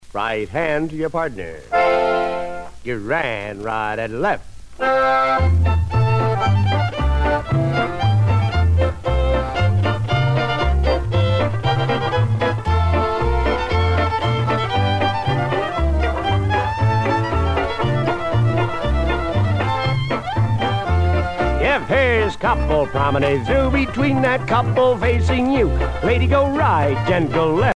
danse : square dance
Pièce musicale éditée